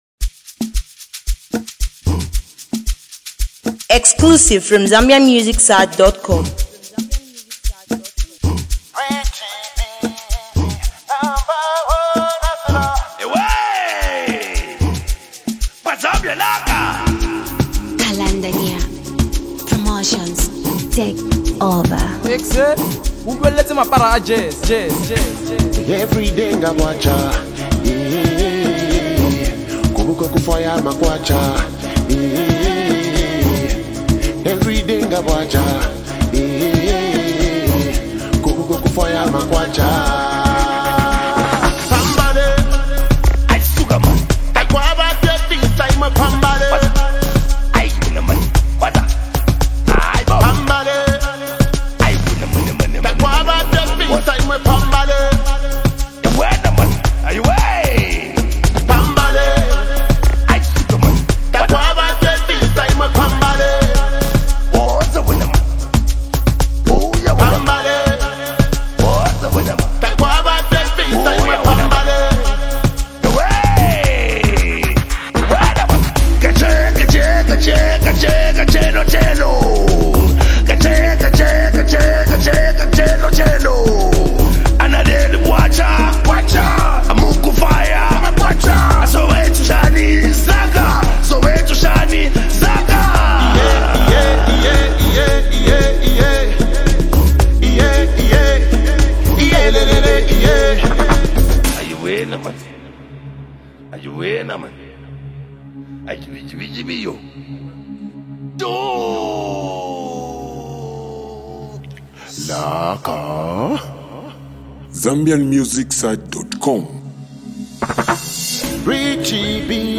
it’s a nice happening song with a great vibe